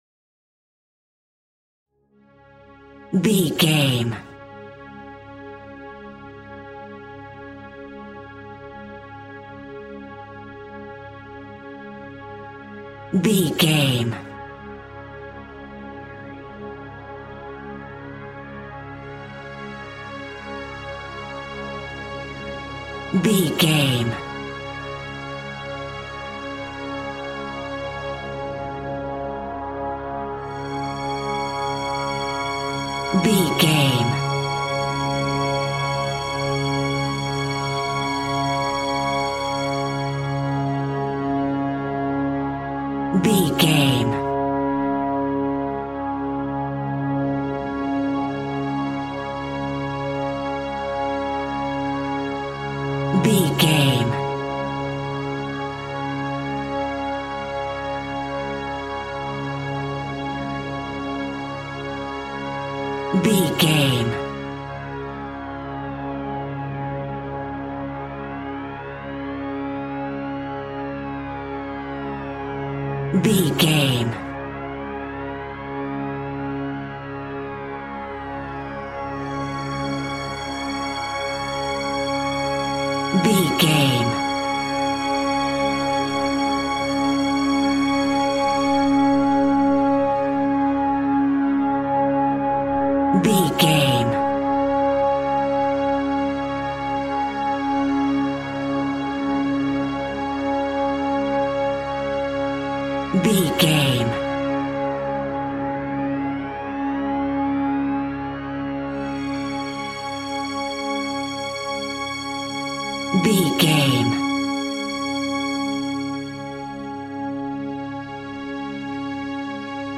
Aeolian/Minor
C#
tension
ominous
dark
suspense
haunting
eerie
synthesizer
Synth Pads
atmospheres